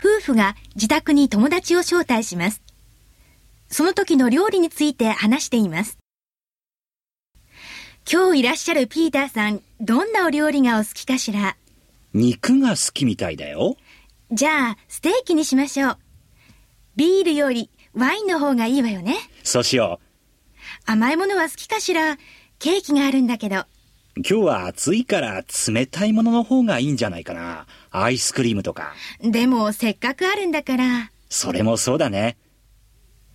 Conversation 1